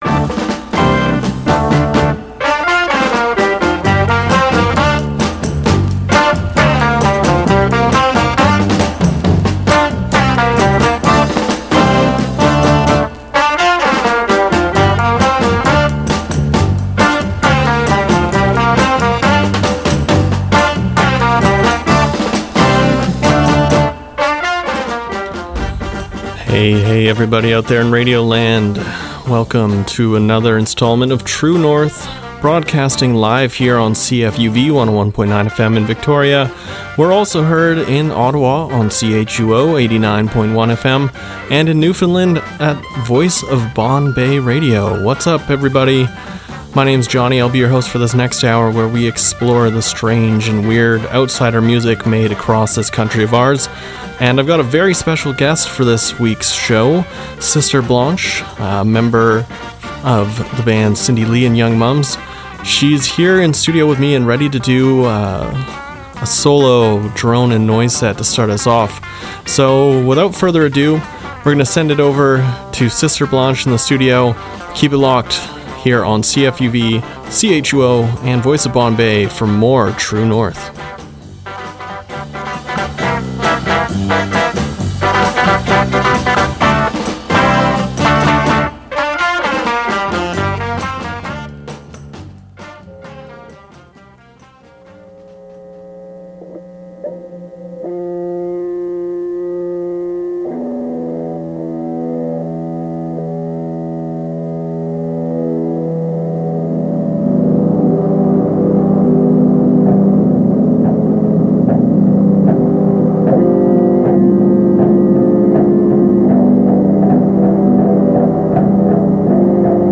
An hour of strange, experimental and independent Canadian rock and pop